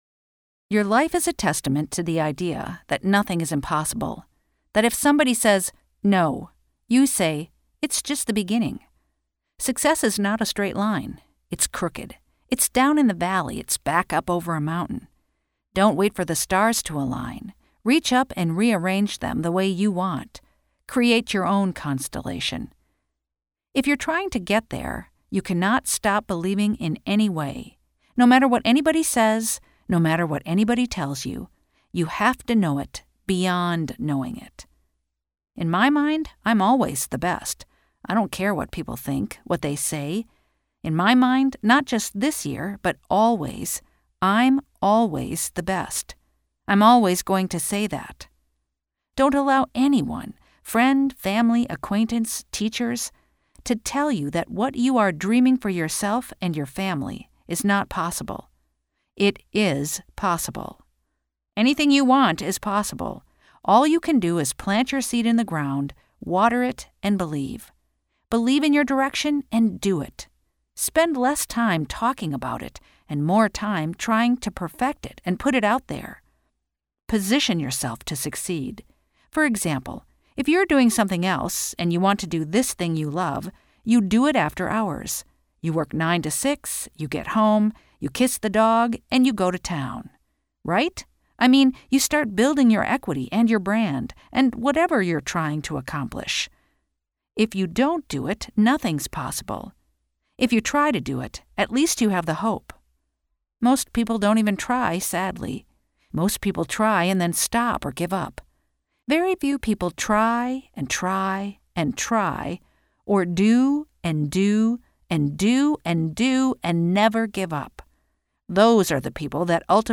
Nesses casos, estamos mantendo a transcrição e a tradução, e disponibilizando para você um áudio, gravado por uma falante nativa.